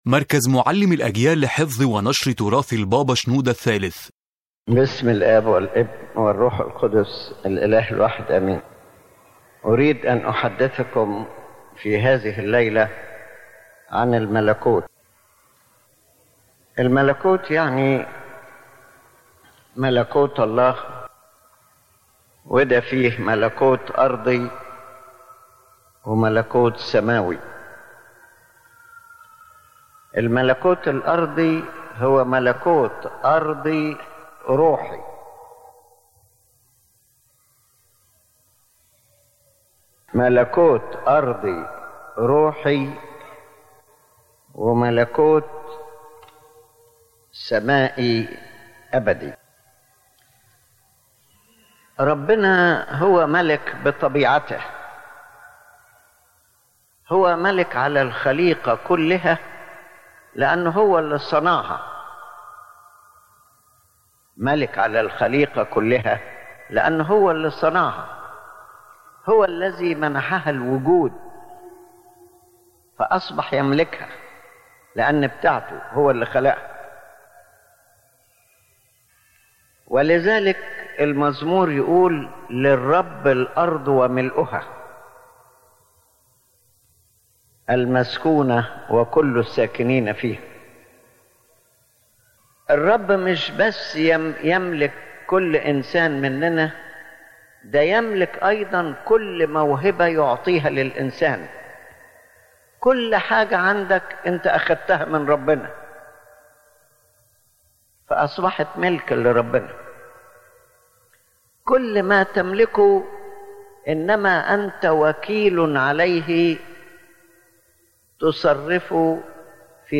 His Holiness Pope Shenouda III presents a spiritual and educational explanation about angels and their nature and works, clarifying their role in the Kingdom of God, their place in the Holy Bible, and how their complete obedience to God represents a spiritual example for believers.
The Main Idea of the Lecture